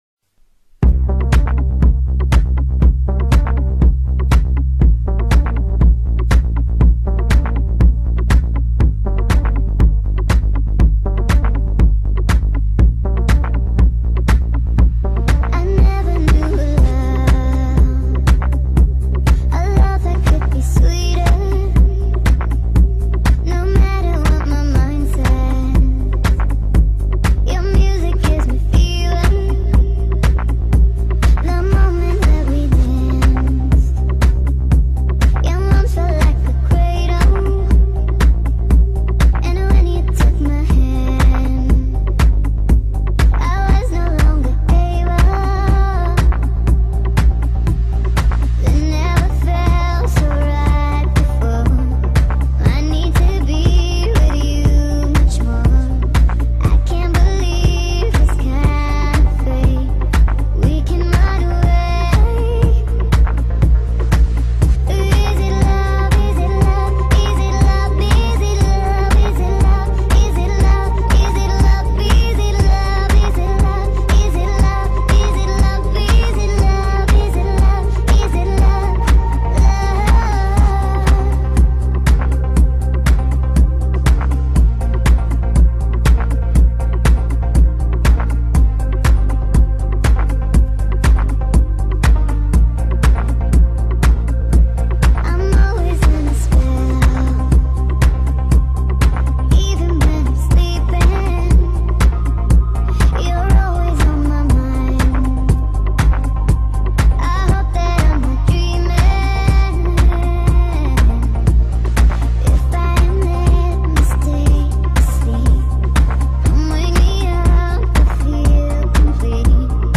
Хаус музыка